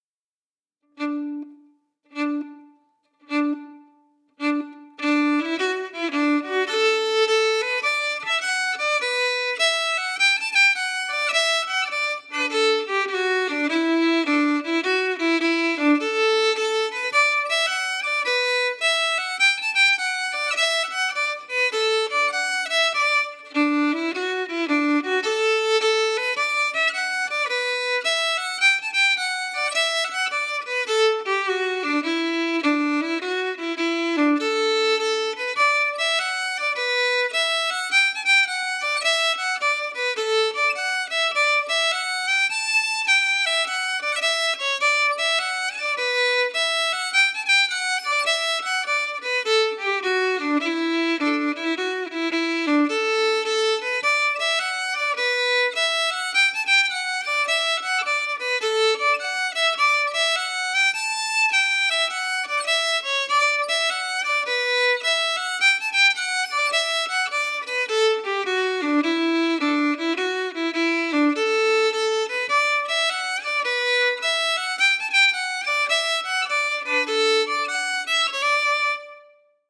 Key: D-major
Form: Hornpipe
Played slowly for learning
Genre/Style: Irish hornpipe